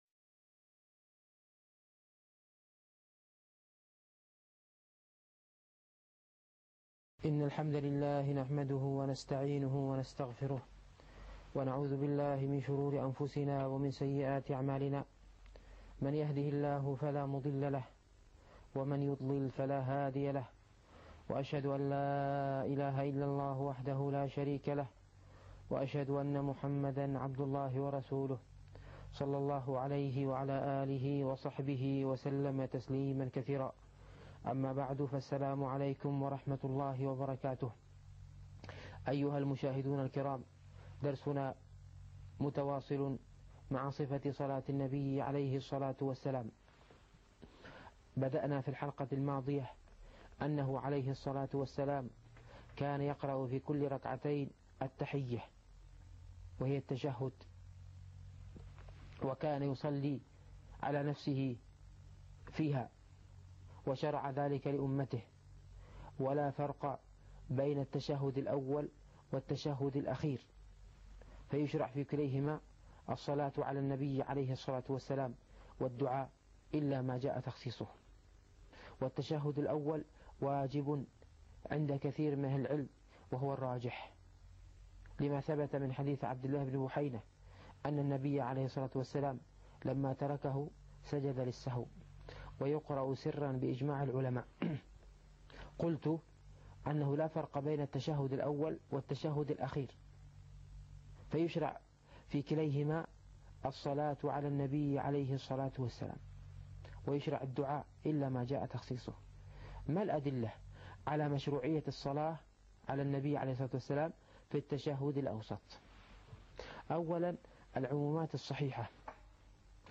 الفقه الميسر - الدرس الرابع والثلاثون